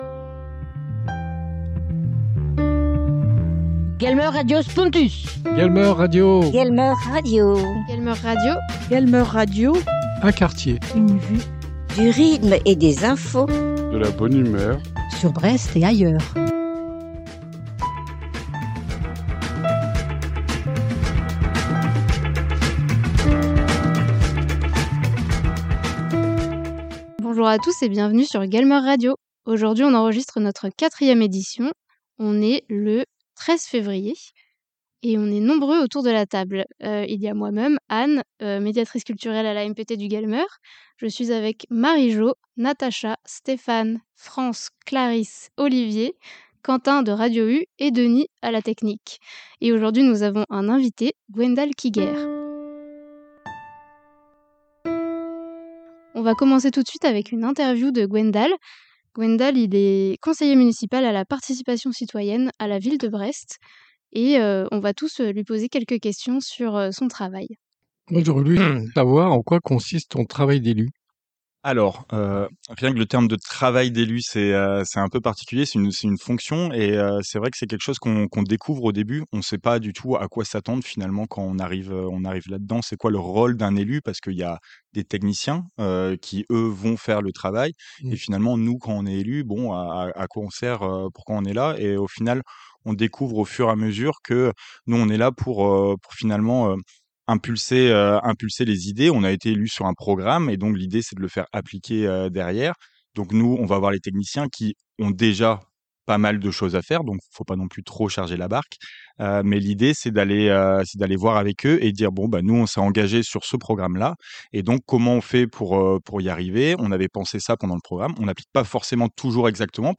Pour cette émission l’équipe de GuelmeuRadio a interviewé Gwendal Quiguer, élu à la ville de Brest sur les questions liées à la participation citoyenne. En tant qu’ancien salarié de "Brest à Pied et à Vélo" (BaPaV) il a également pu répondre à des questions concernant la mobilité à Brest et dans le quartier de Saint Marc. Vous entendrez également un reportage réalisé à la Guinguette d’Astropolis.